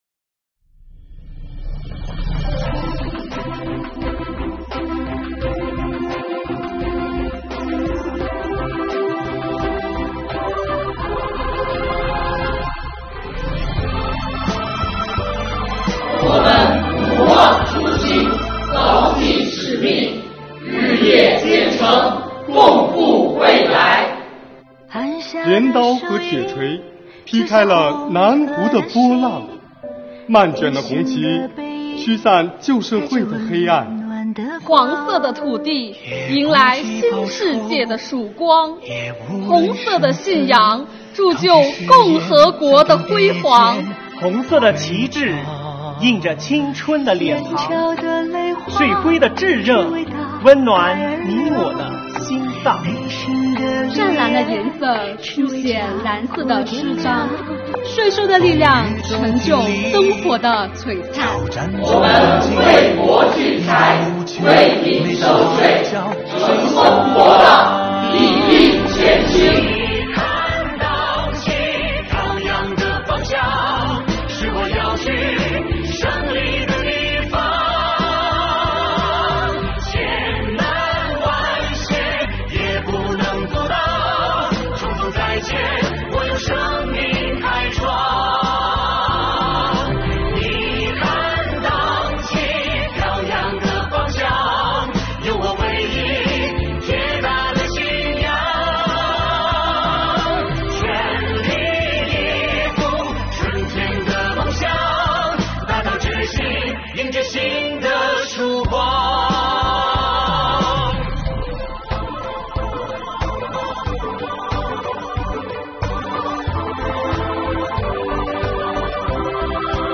视频音乐MV：《党旗飘扬的方向》